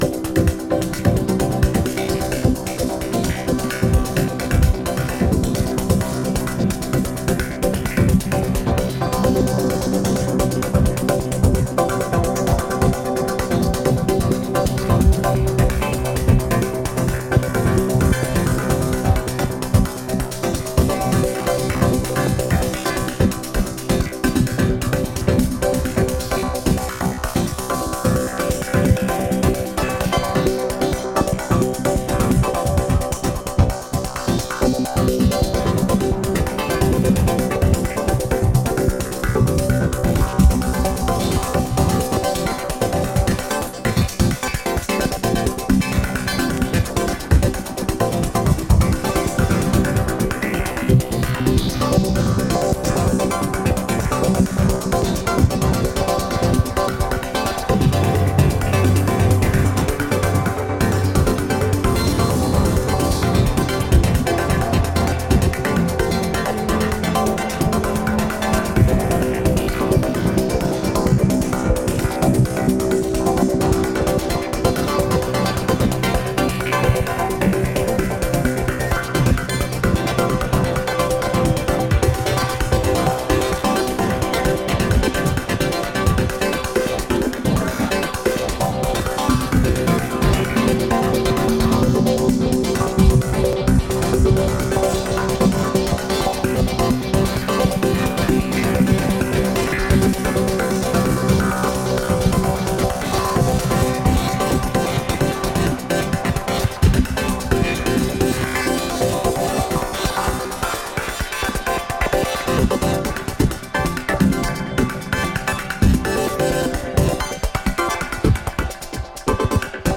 なんといっても殆どキックが入らないトランシーでエクスタティックな構成がたまらないです。